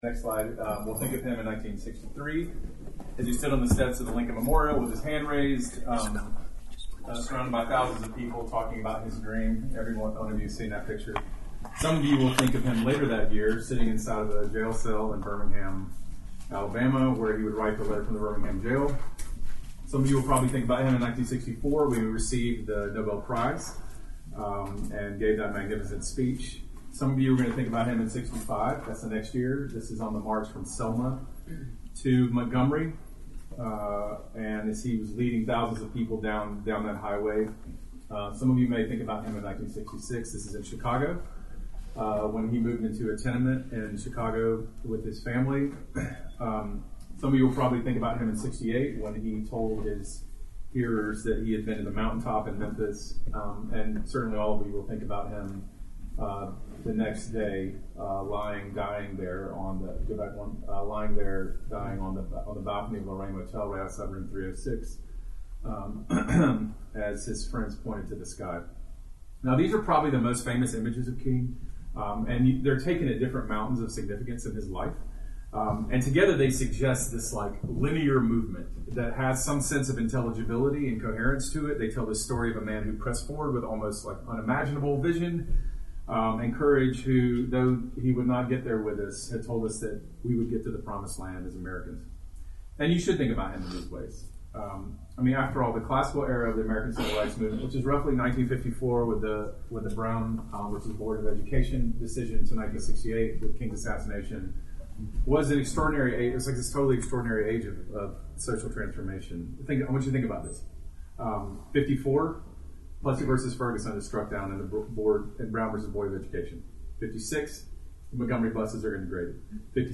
Audio Information Date Recorded: December 2018 Location Recorded: Charlottesville, VA Audio File: Download File » This audio is published by the Project on Lived Theology (PLT).